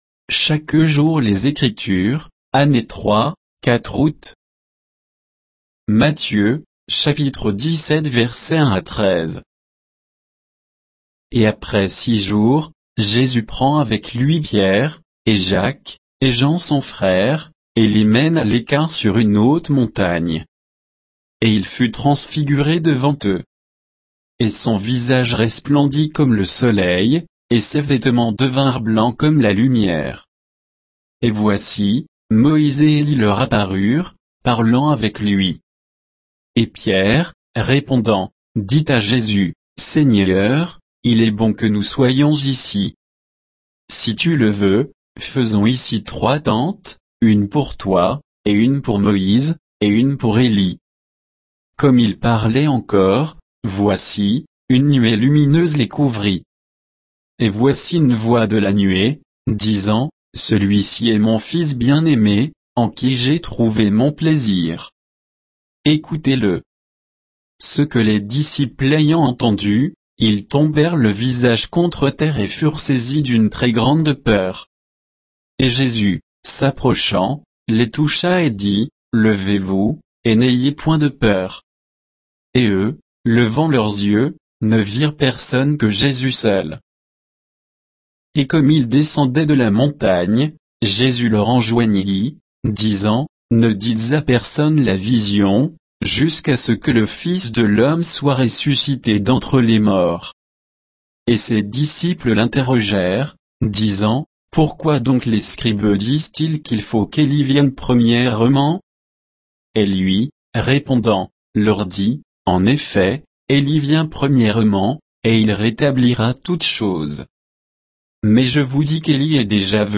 Méditation quoditienne de Chaque jour les Écritures sur Matthieu 17, 1 à 13